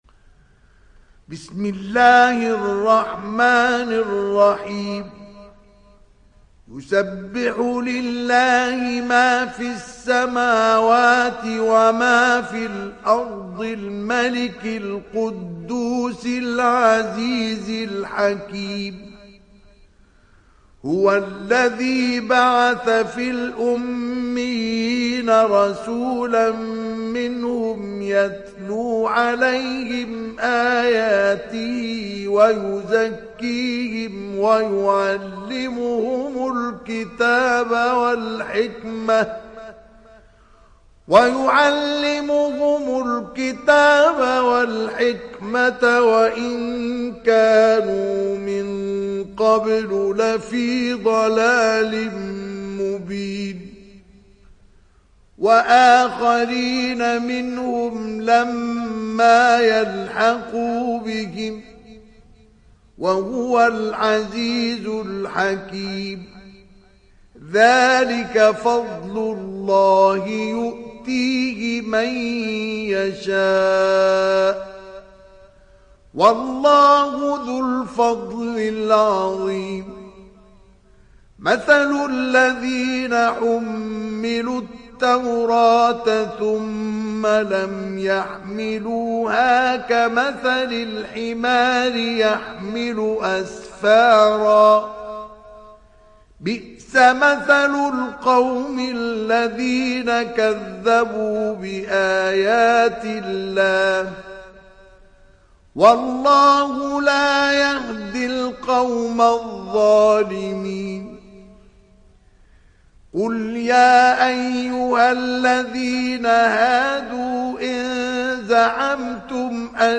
دانلود سوره الجمعه mp3 مصطفى إسماعيل روایت حفص از عاصم, قرآن را دانلود کنید و گوش کن mp3 ، لینک مستقیم کامل